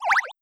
Water1.wav